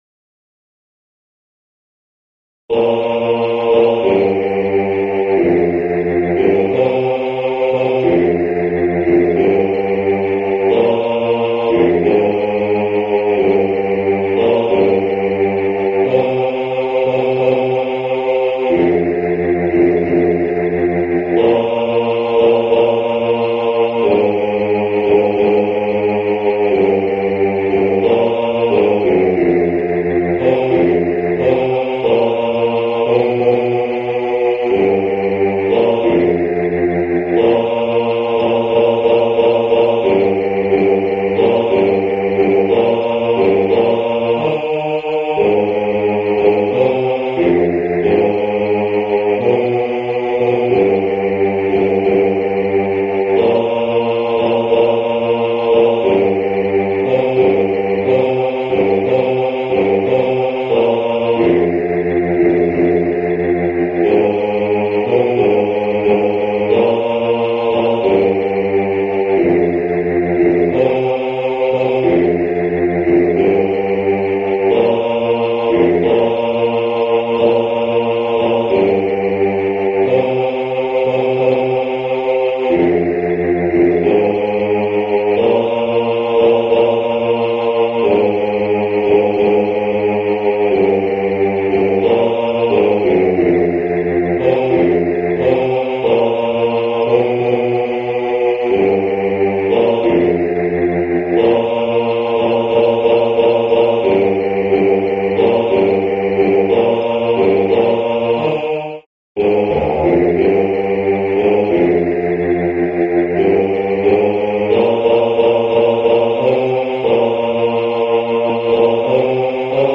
Coro musica afroamericana, blues, swing, spirituals - Bologna